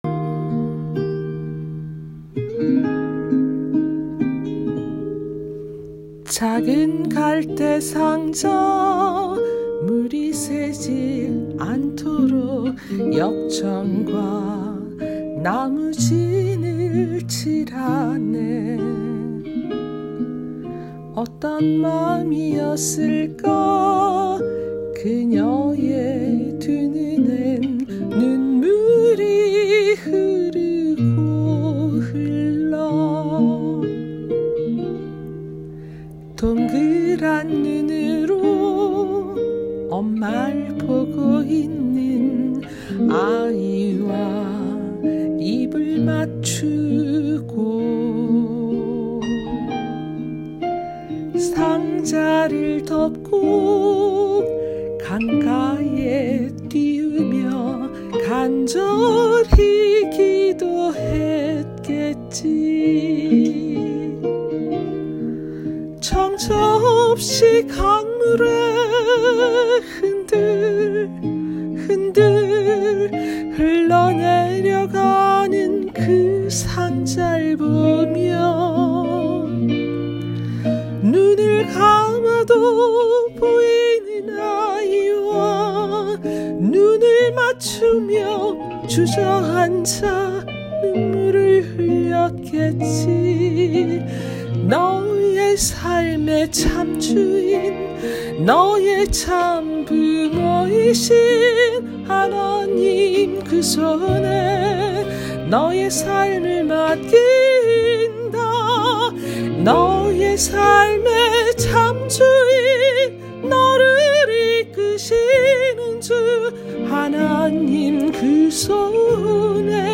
2021년 3월 14일 주일 특송. ‘요게벳의 노래’
워싱턴 하늘비전교회 2021년 3월 14일 특송.